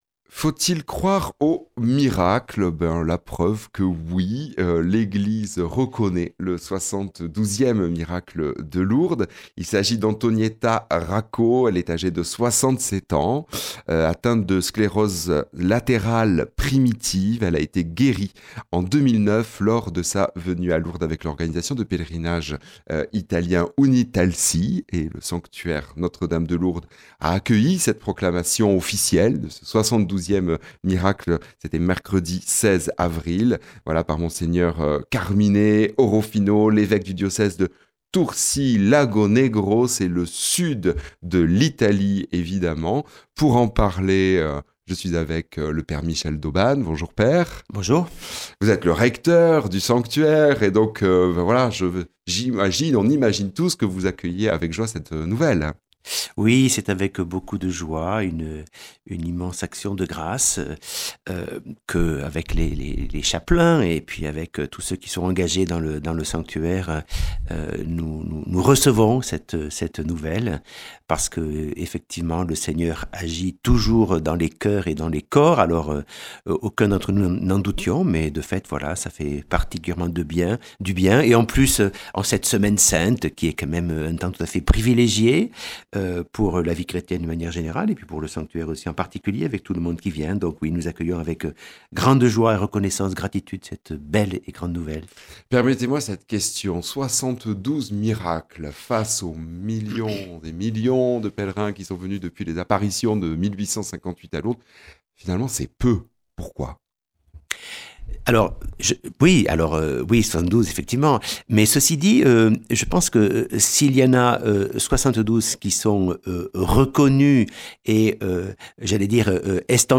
Le Sanctuaire Notre-Dame de Lourdes a accueilli la proclamation officielle du 72 miracle de Lourdes, le mercredi 16 avril, par Mgr Vincenzo Carmine Orofino, évêque du diocèse de Tursi-Lagonegro Province de MATERA, Italie. Rencontre